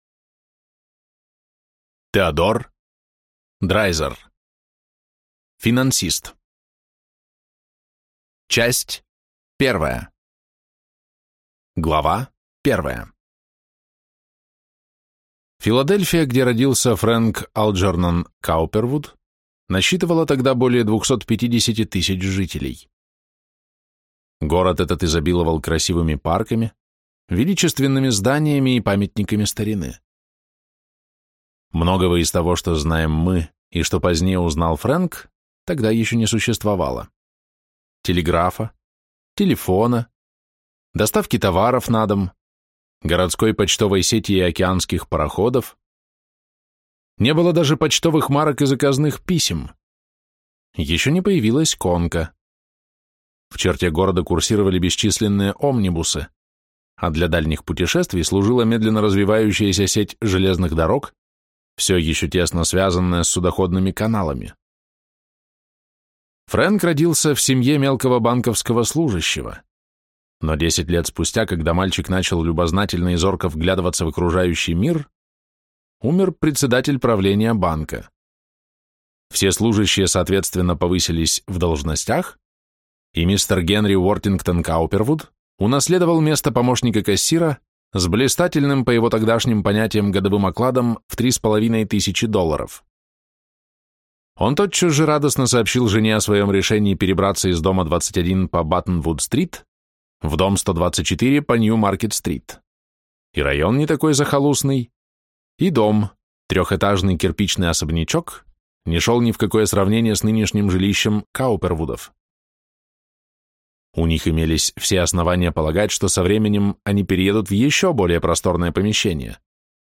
Аудиокнига Финансист. Часть 1 | Библиотека аудиокниг